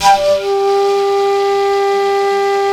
FLUTELIN15.wav